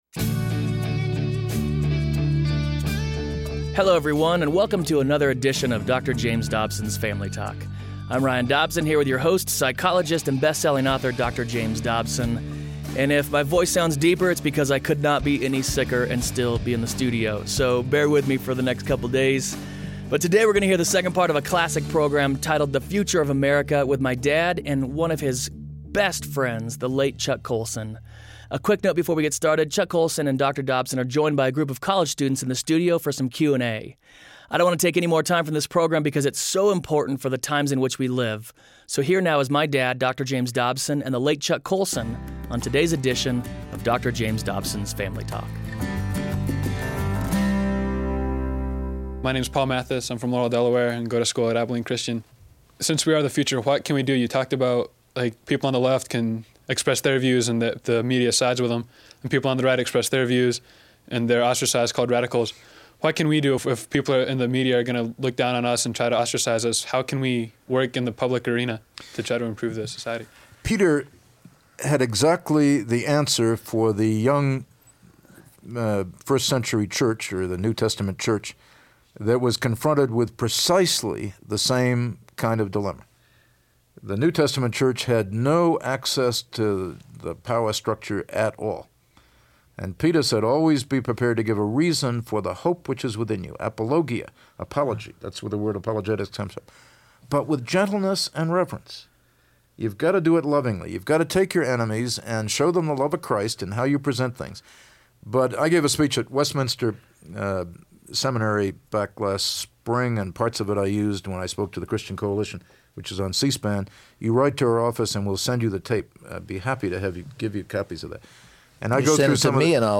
Its scary when someone predicts a shift in the direction of our country and ends up being correct. On this classic edition of FamilyTalk, the late Chuck Colson and Dr. Dobson talk about the future of America.